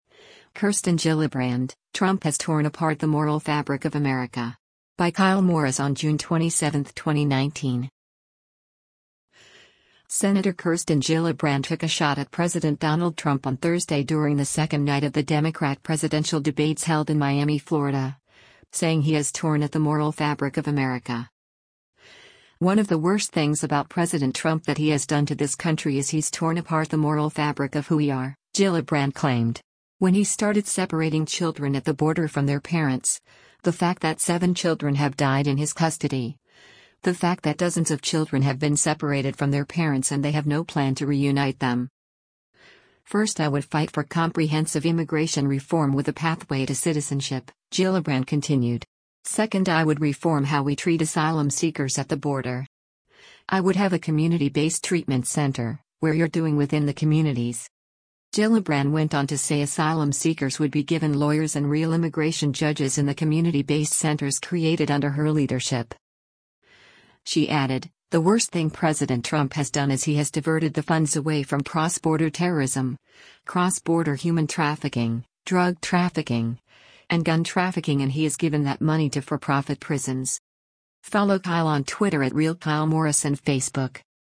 Sen. Kirsten Gillibrand took a shot at President Donald Trump on Thursday during the second night of the Democrat presidential debates held in Miami, Florida, saying he has “torn at the moral fabric” of America.